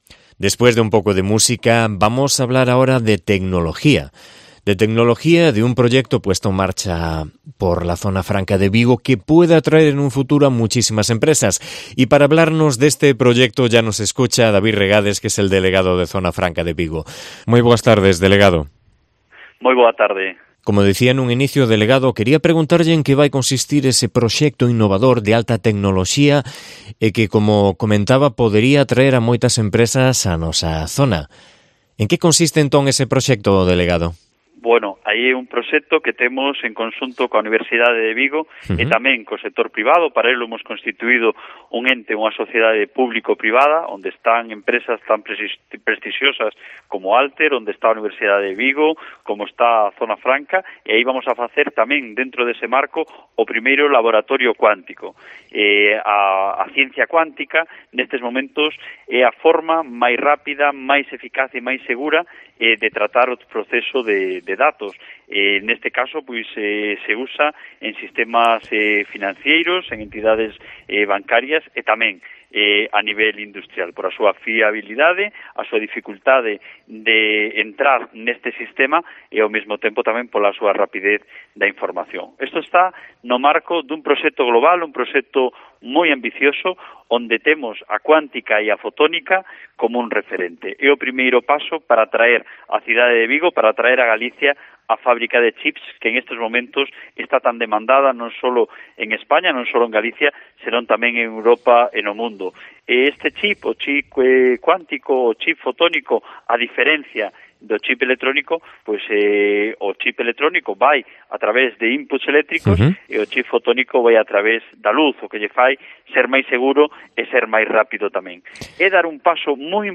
Entrevista a David Regades, delegado de Zona Franca de Vigo